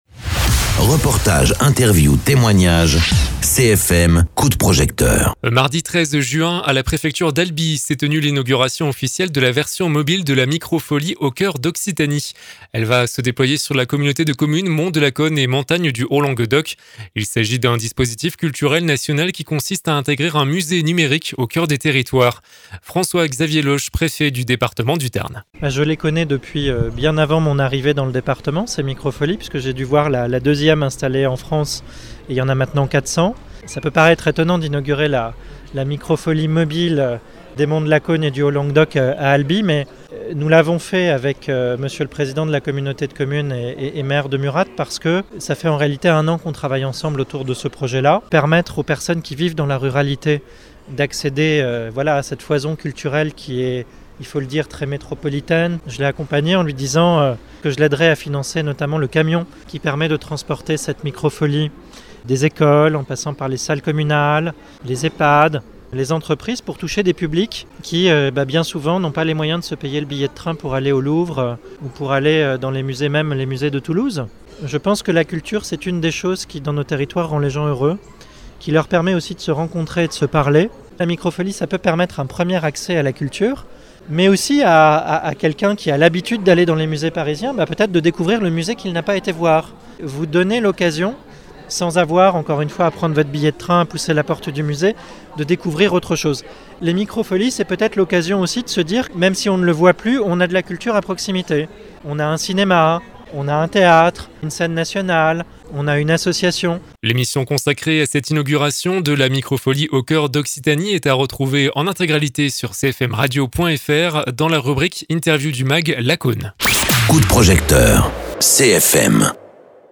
Interviews
Invité(s) : François-Xavier Lauch, préfet du département du Tarn.